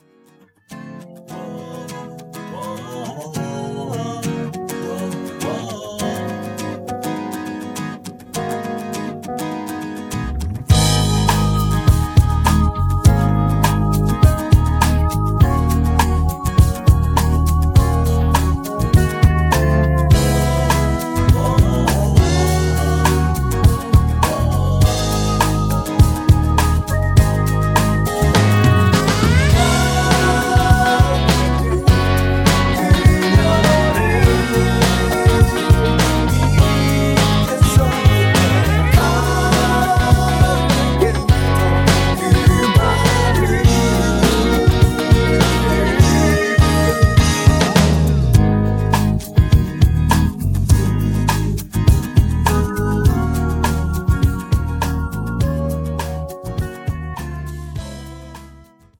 음정 -1키 3:07
장르 가요 구분 Voice MR